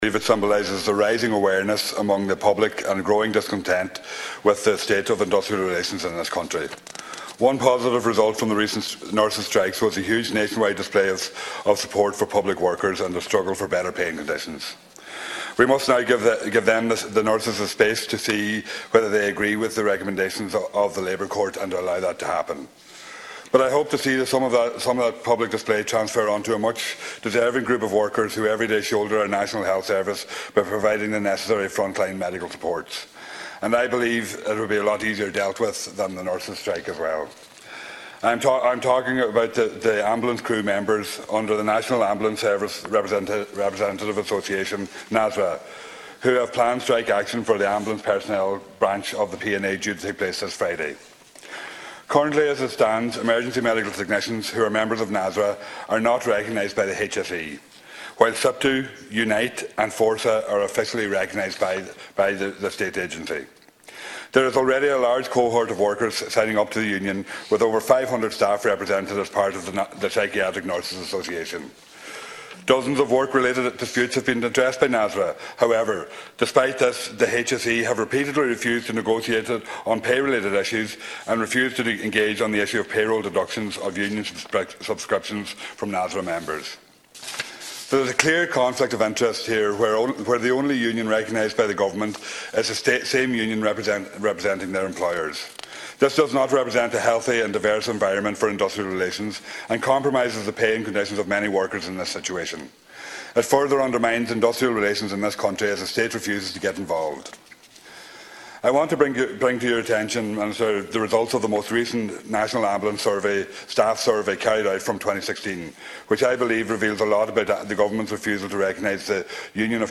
Speaking in the Dail this week, Donegal Deputy Thomas Pringle urged the Government to initiate negotiations between the HSE and NASRA personnel: